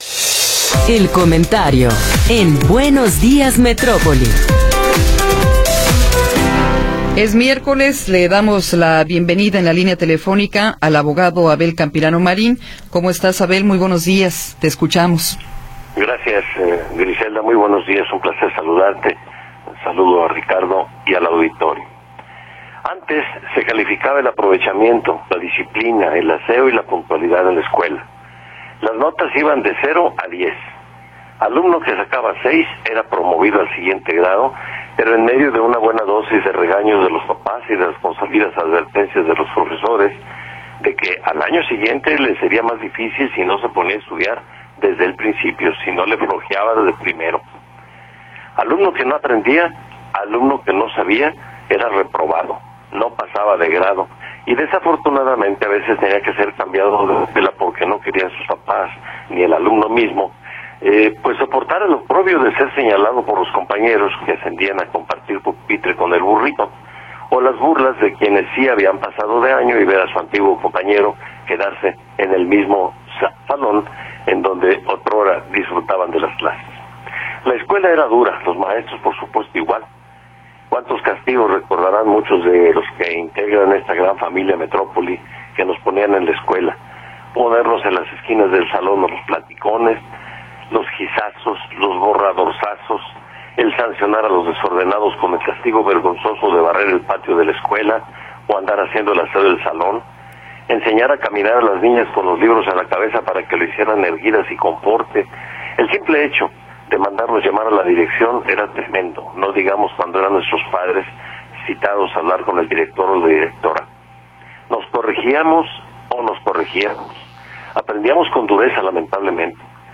Comentario